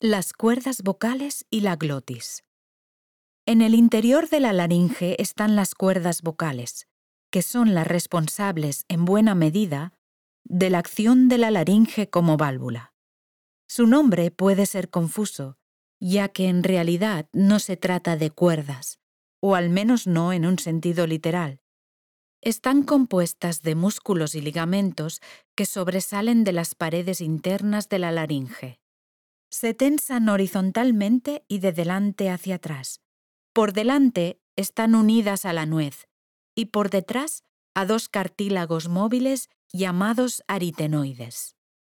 I have a home studio at home where I can record, edit, and mix my vocals.
Spanish (Eu-Castilian) Voice Talent & Singer.
kastilisch
Sprechprobe: eLearning (Muttersprache):